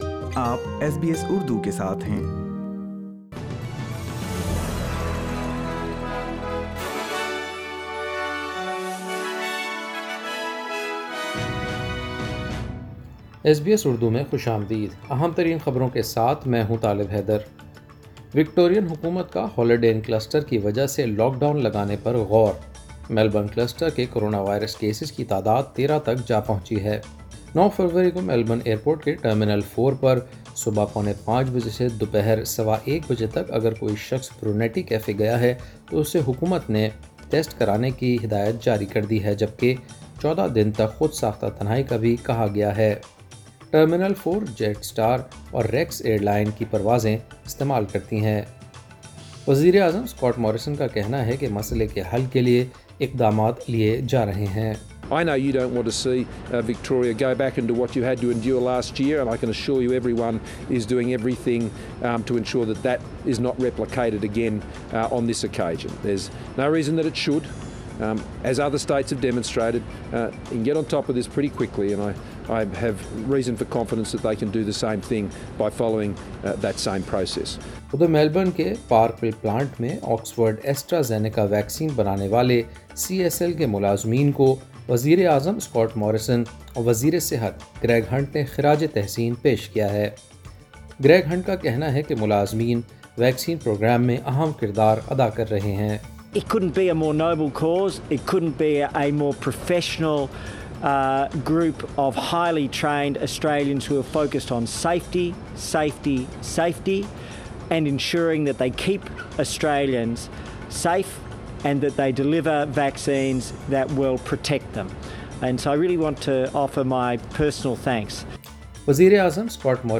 ایس بی ایس اردو خبریں 12 فروری 2021